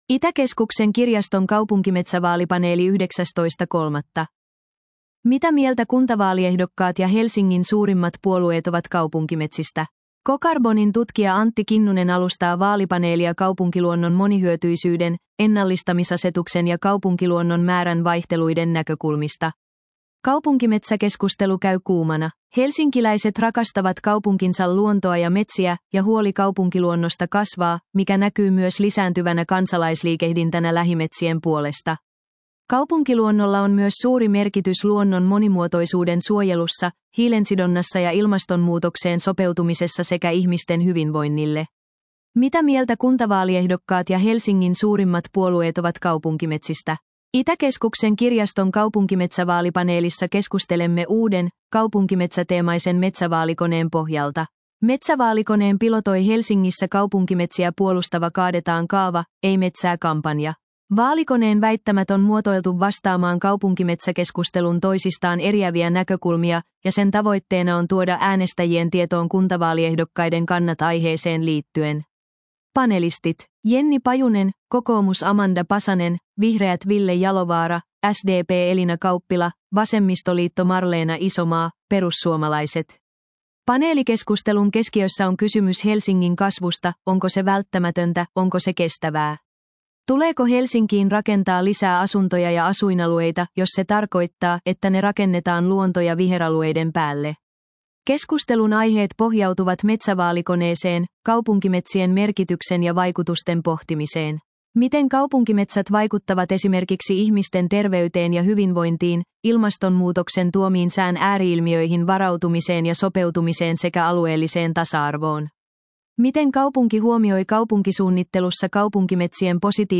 Itäkeskuksen kirjaston kaupunkimetsävaalipaneeli 19.3.